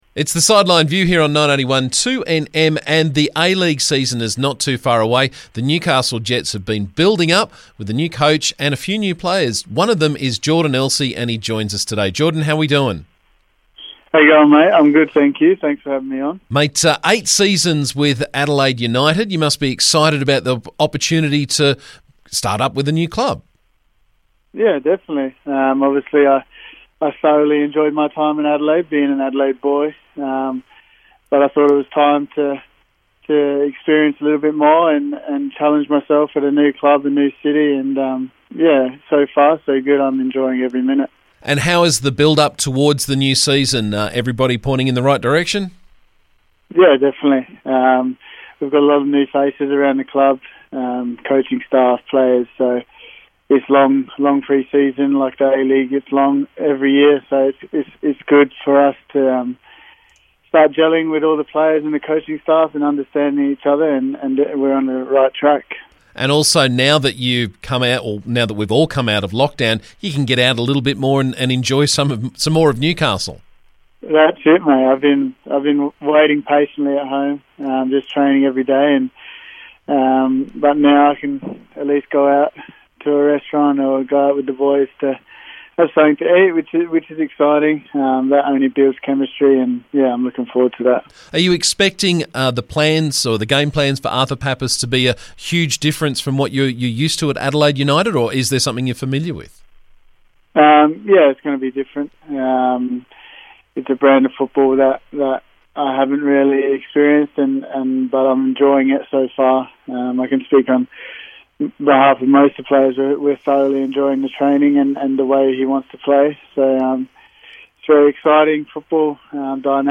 After 8 seasons with Adelaide United, central defender Jordan Elsey has moved to the Newcastle Jets. He was on The Sideline View today to talk about his expectations for the season with his new club.